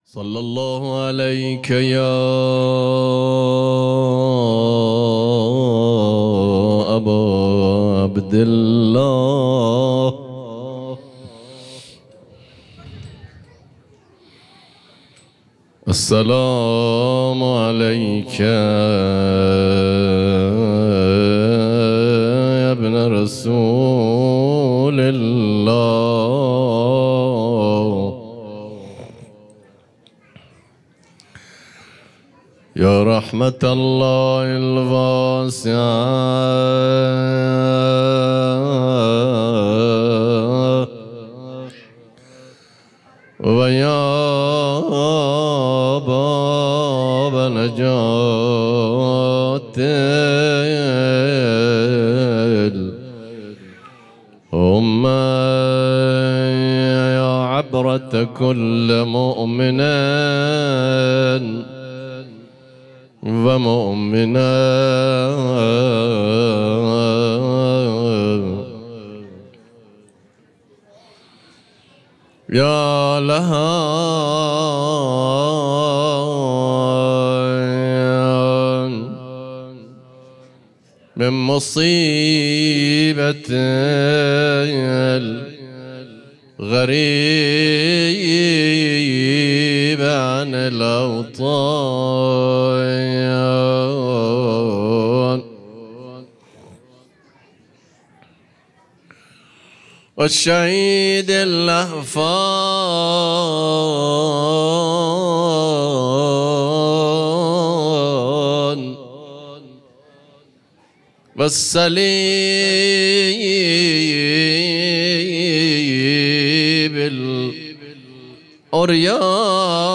خیمه گاه - هیئت محبان الحسین علیه السلام مسگرآباد - روضه بخش اول